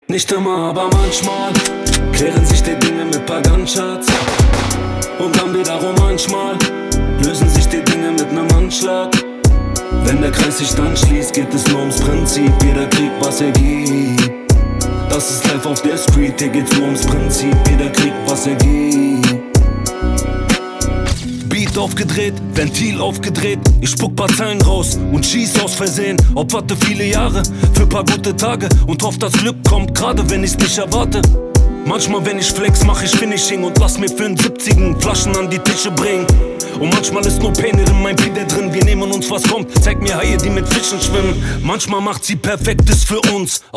Kategorien Rap